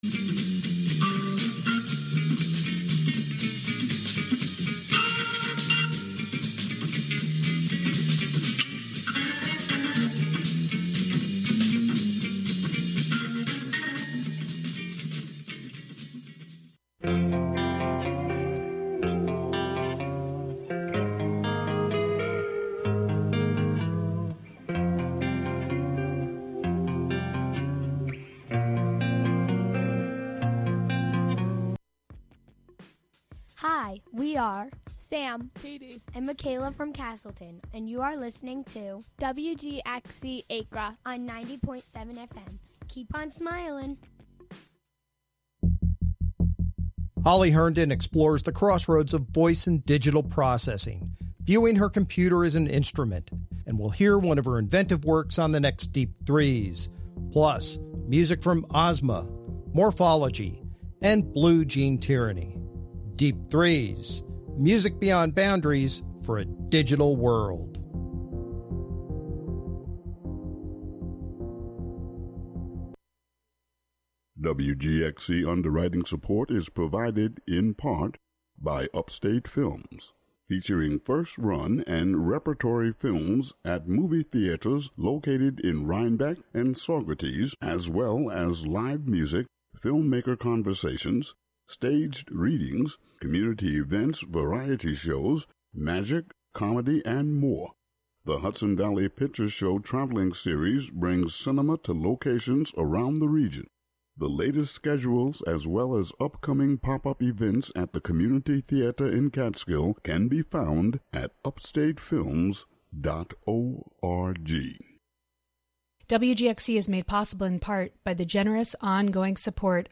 An upbeat music show featuring the American songbook
jazz